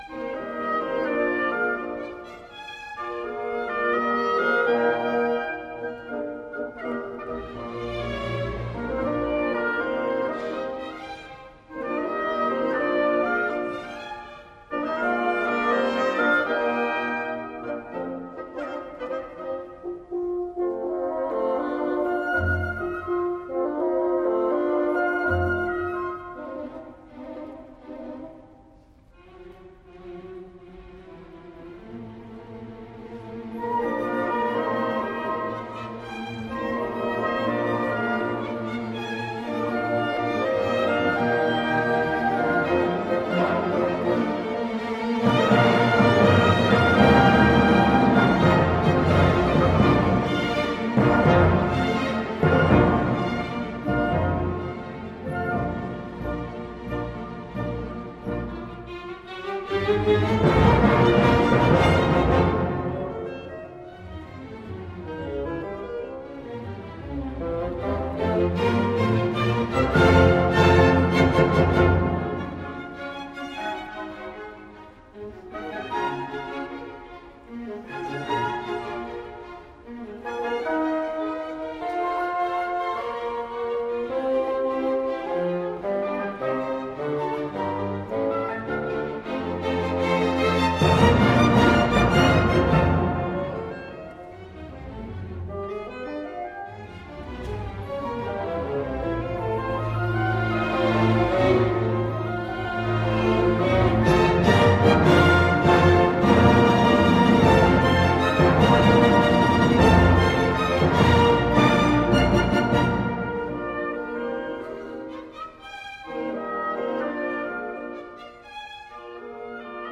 Sinfonie_Nr_4_B-Dur_op_-60_-_Allegro-vivace_-_Trio_un-poco-meno-Allegro.mp3